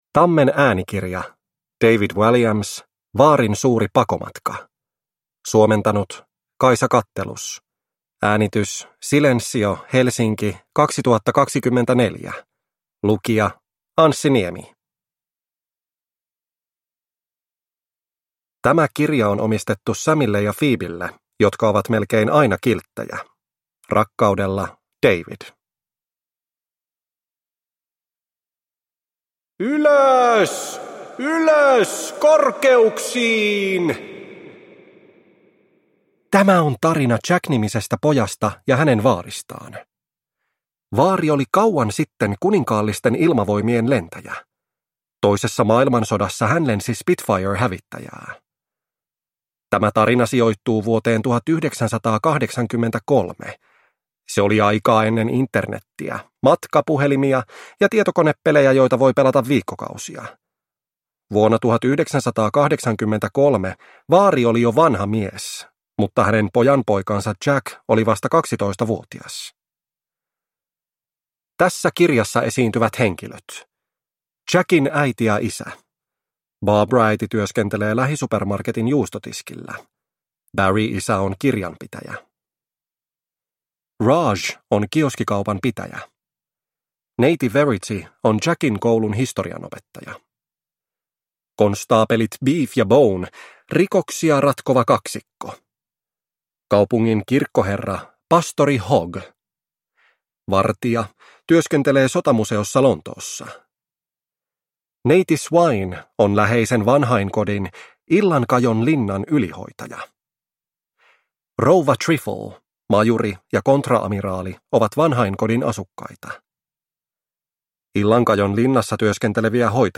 Vaarin suuri pakomatka – Ljudbok